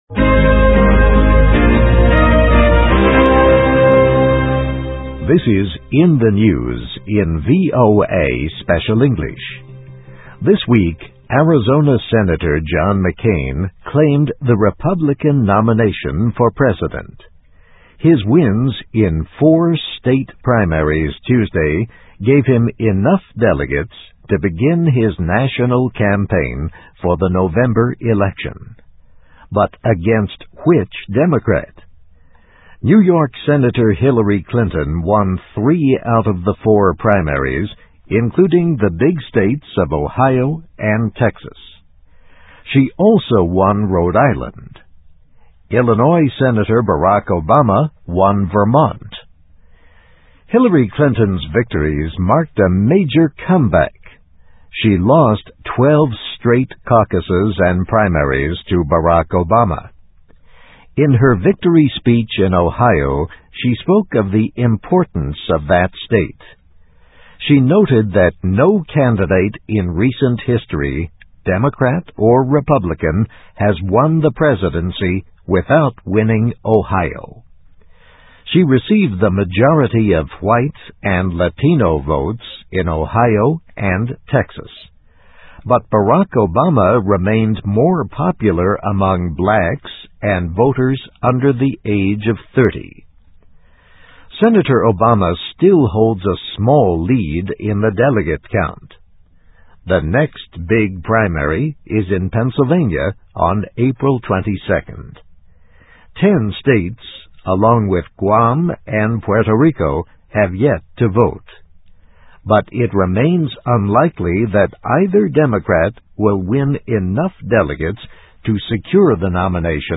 McCain Can Launch National Campaign; Democrats Still Stuck (VOA Special English 2008-03-08)